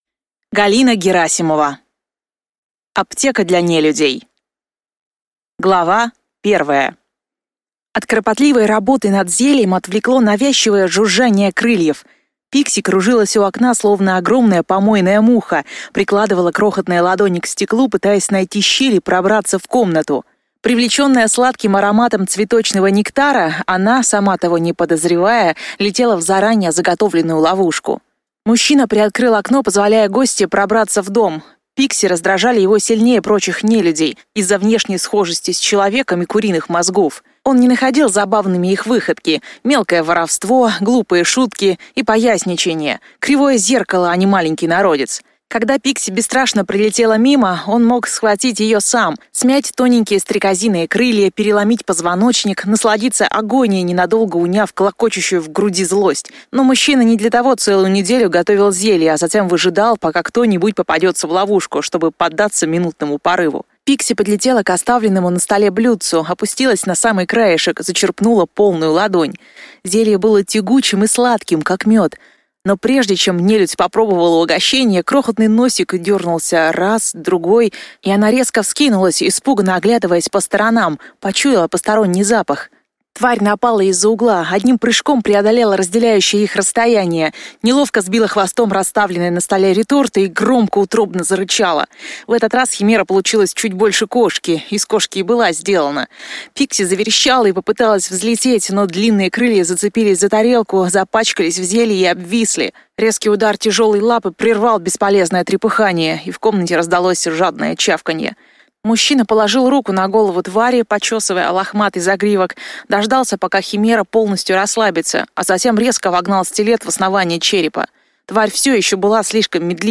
Аудиокнига Аптека для нелюдей | Библиотека аудиокниг